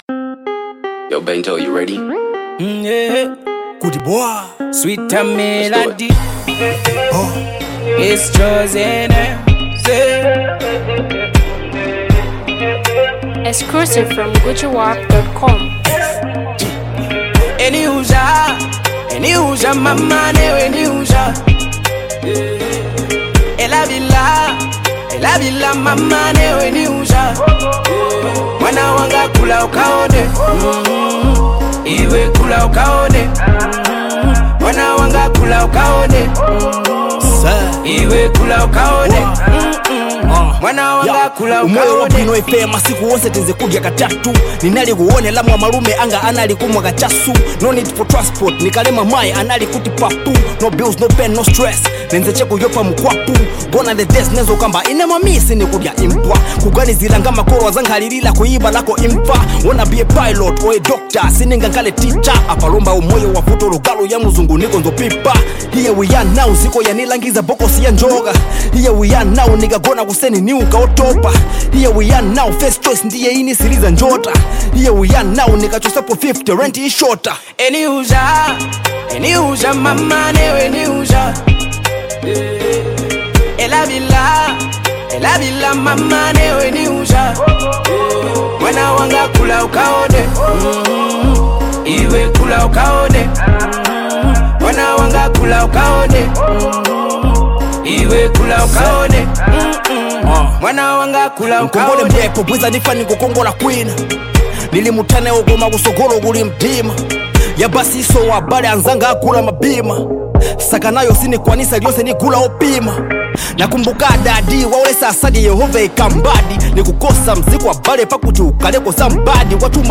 anthem of love melodic rhyme
soulful hit record
enchanting melody